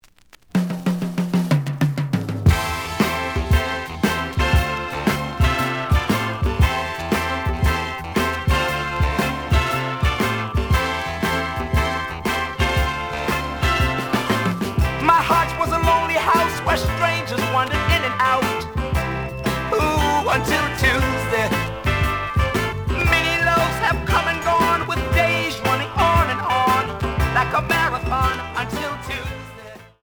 試聴は実際のレコードから録音しています。
●Genre: Soul, 70's Soul
●Record Grading: VG (盤に歪み。プレイOK。)